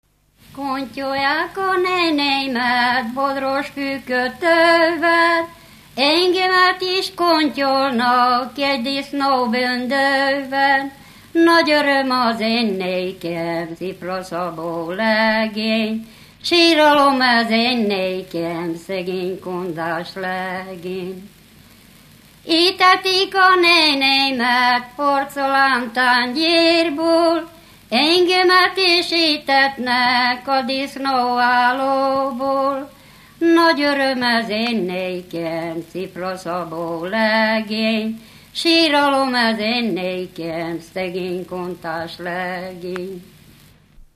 Erdély - Szilágy vm. - Kárásztelek
ének
Műfaj: Ballada
Stílus: 1.1. Ereszkedő kvintváltó pentaton dallamok